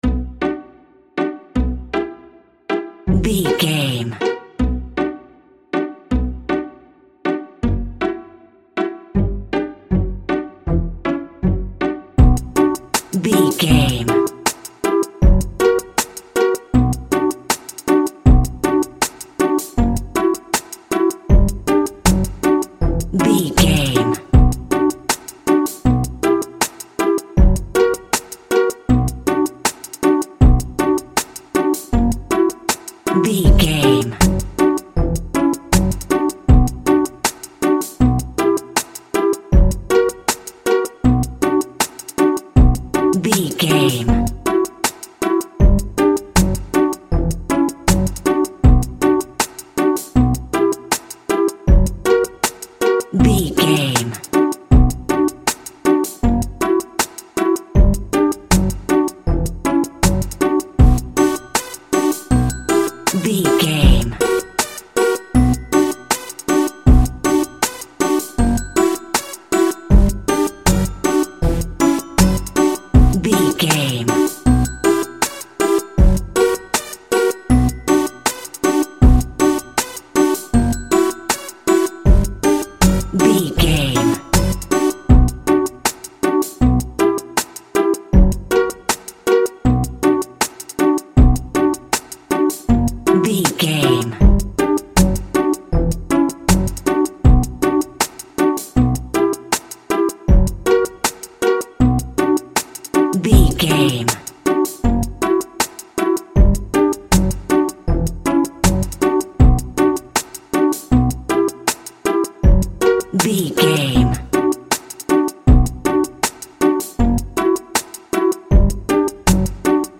Aeolian/Minor
ominous
dark
haunting
eerie
urban
strings
drum machine
synthesiser
electric piano
creepy
instrumentals
horror music